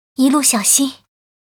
文件 文件历史 文件用途 全域文件用途 Erze_fw_05.ogg （Ogg Vorbis声音文件，长度1.4秒，83 kbps，文件大小：15 KB） 源地址:地下城与勇士游戏语音 文件历史 点击某个日期/时间查看对应时刻的文件。